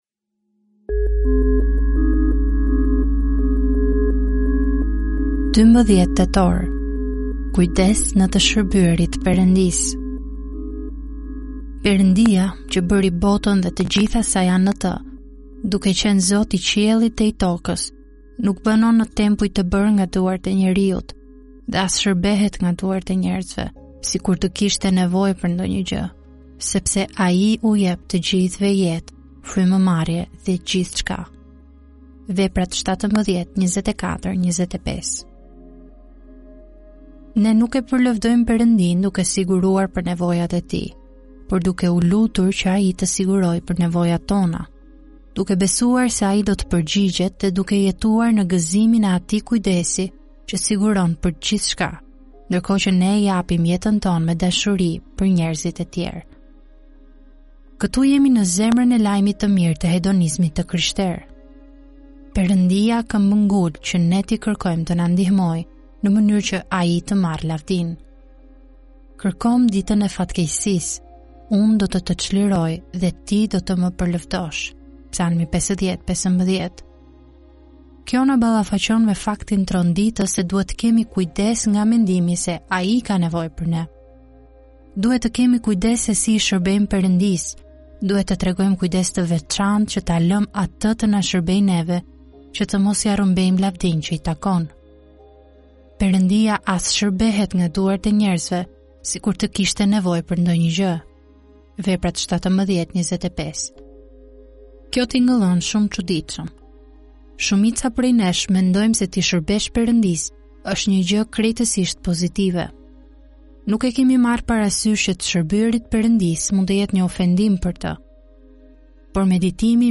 "Solid Joys" janë lexime devocionale të shkruara nga autori John Piper.